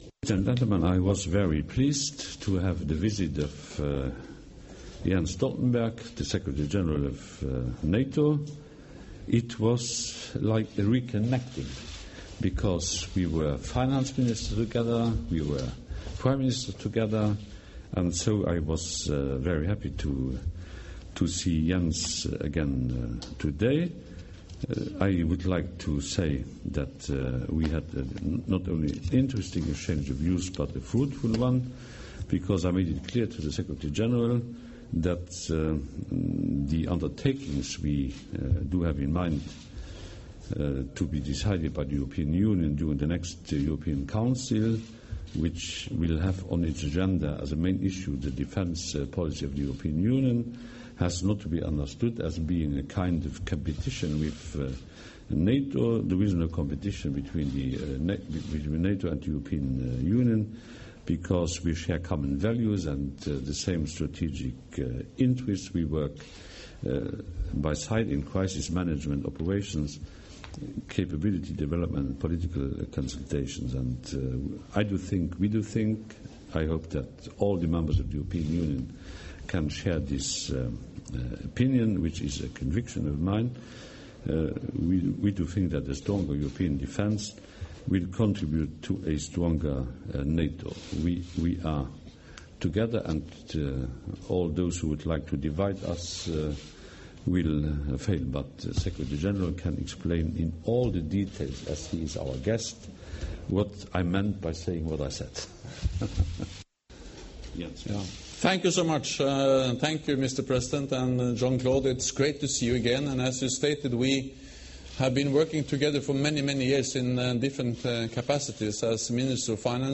Joint press point with NATO Secretary General Jens Stoltenberg and the President of the European Commission, Jean-Claude Juncker